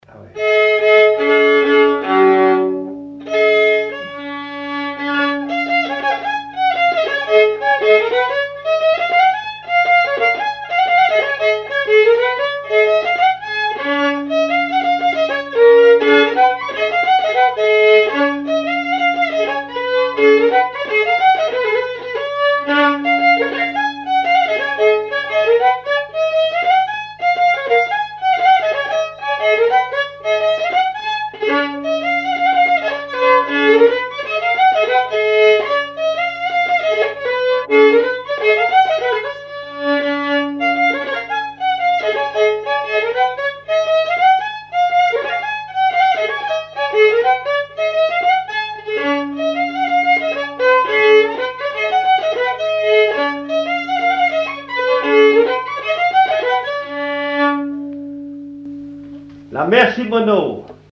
danse : branle : avant-deux
répertoire d'air pour la danse au violon et à l'accordéon
Pièce musicale inédite